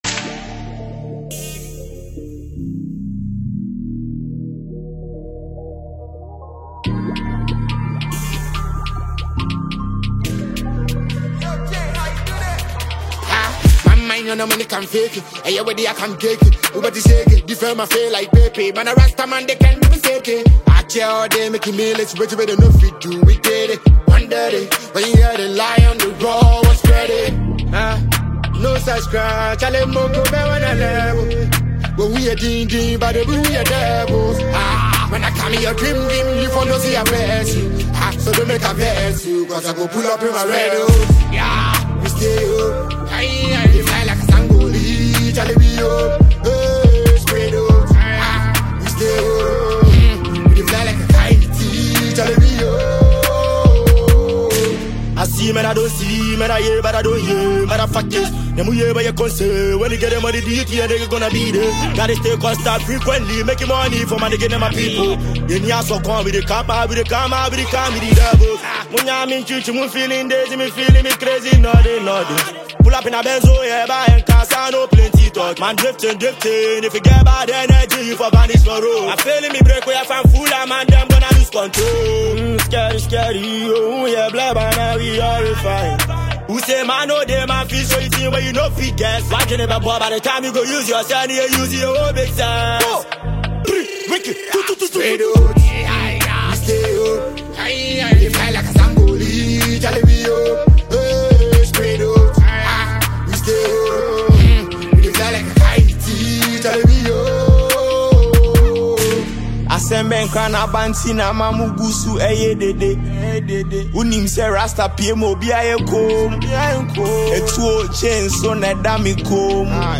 Ghana Music
The Ghanaian Hip-Hop trio